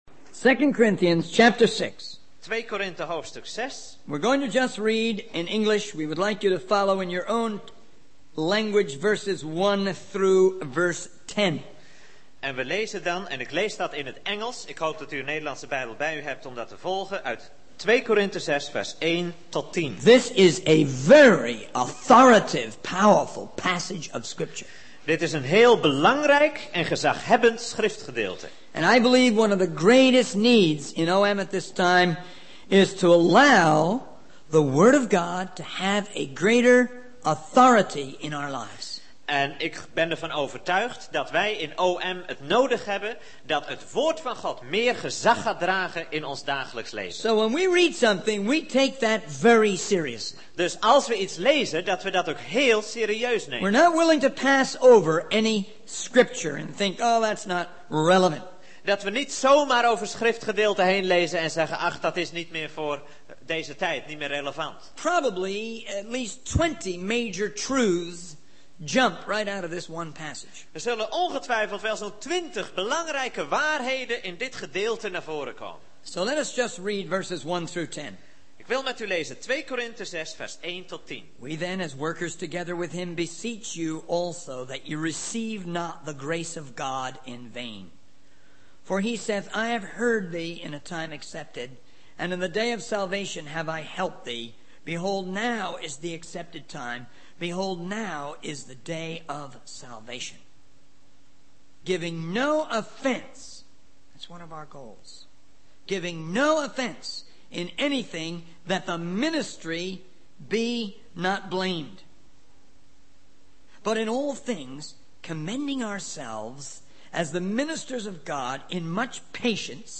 In this sermon, the speaker expresses gratitude to God for the ability to be genuine and authentic in preaching and in personal interactions. They acknowledge the existence of both sorrow and joy in life, citing examples of tragic events that have occurred recently. The speaker emphasizes the importance of patience and how it is a crucial aspect of leadership in the work of God.